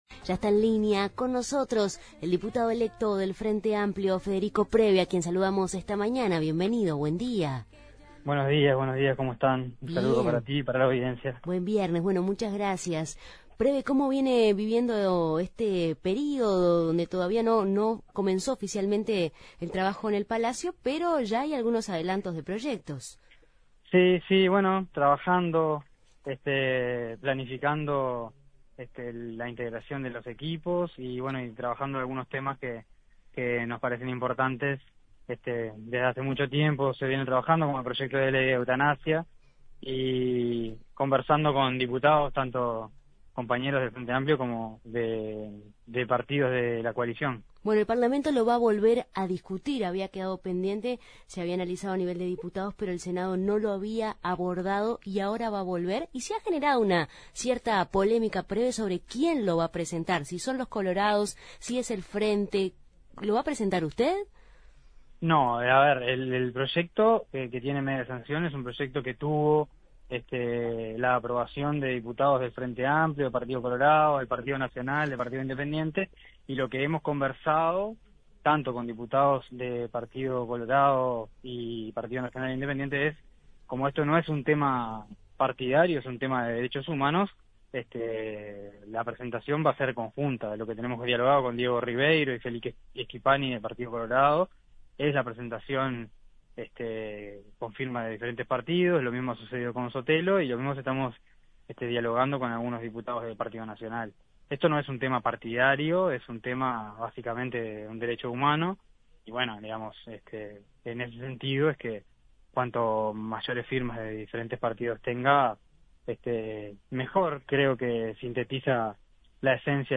En Justos y pecadores entrevistamos al diputado electo por el Frente Amplio, Federico Preve